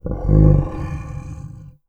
MONSTER_Growl_Subtle_01_mono.wav